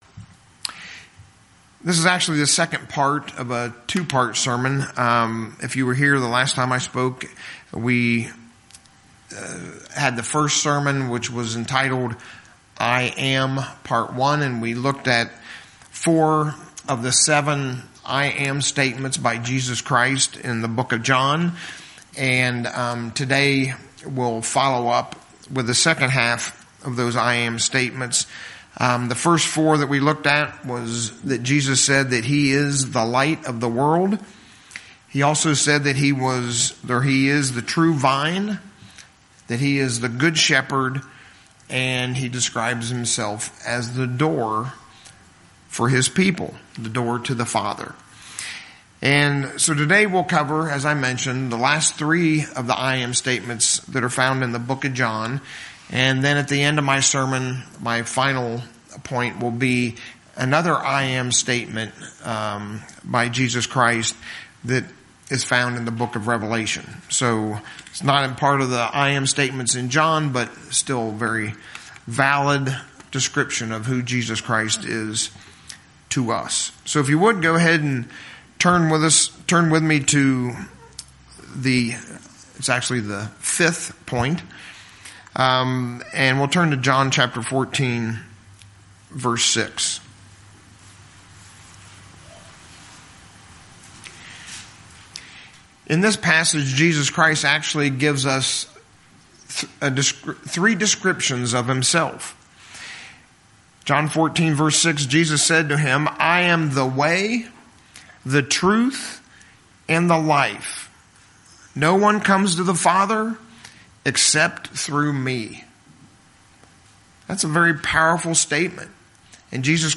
In part two of our sermon looking into the seven "I AM" statements by Jesus, within the Book of John, we'll see just how much Jesus is there for us, in every way that we need Him in our journey towards His Father's Kingdom.
Given in Lehigh Valley, PA